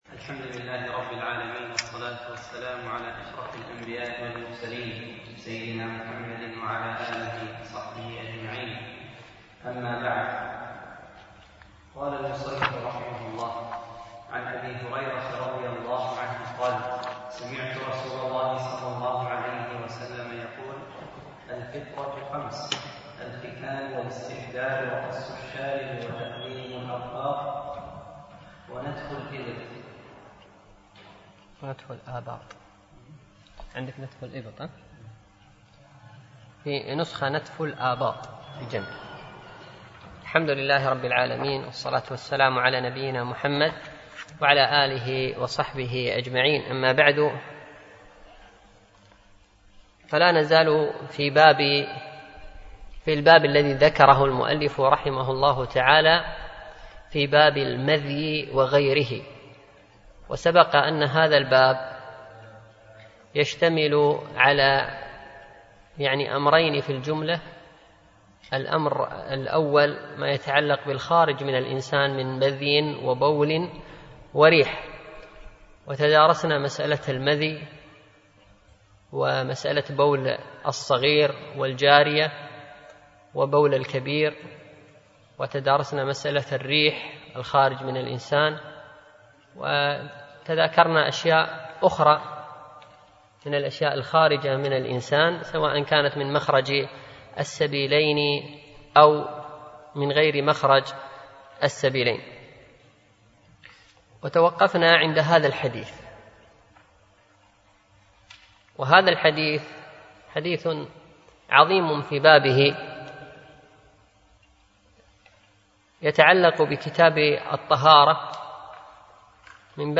شرح عمدة الأحكام ـ الدرس الثامن